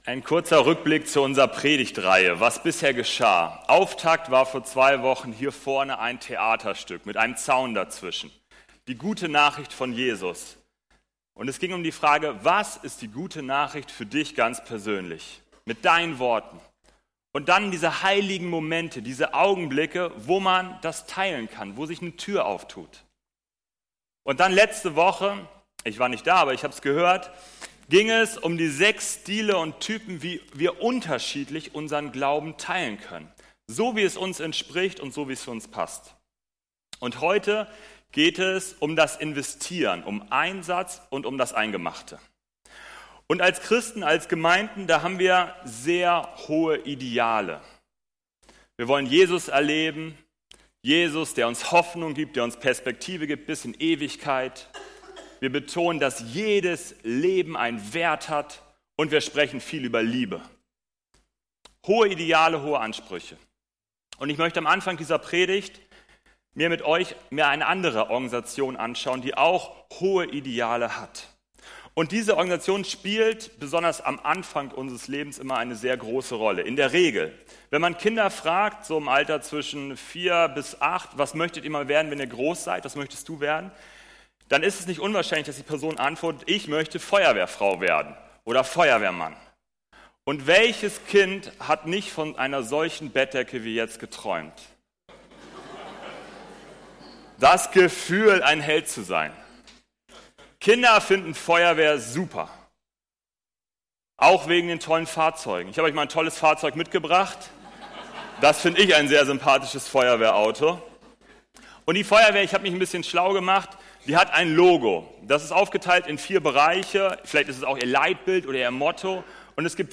Predigttext: 1. Johannes 3,18 Kolosser 4,3-6
Gottesdienst